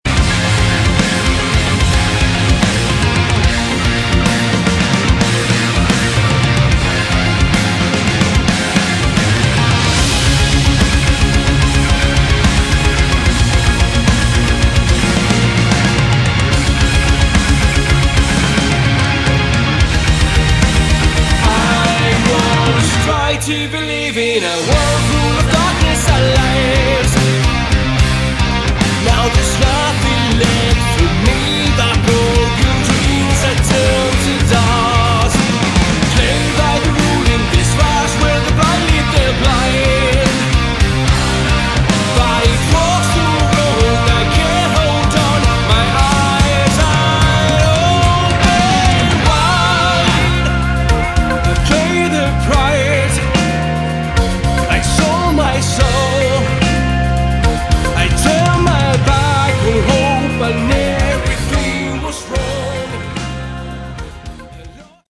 Category: Rock
keyboards, backing vocals